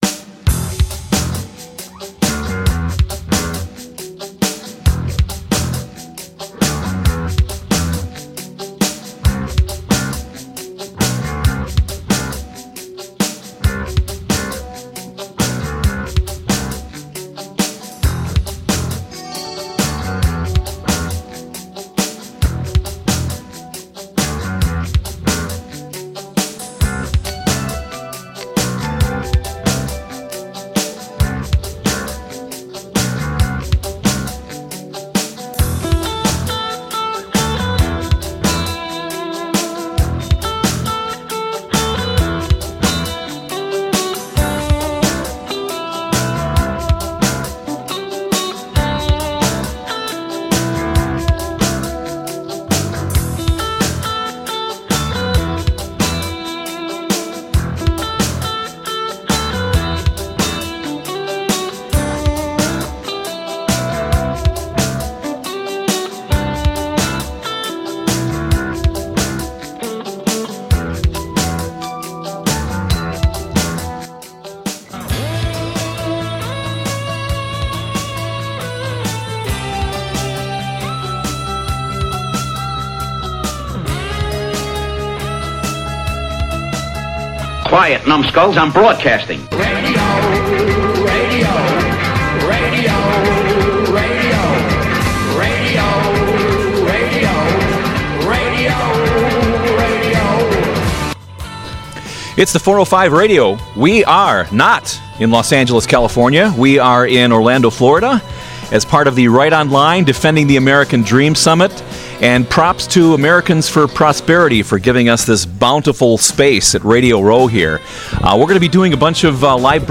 This is a one-hour program, but worth listening to on your computer as you are doing something else.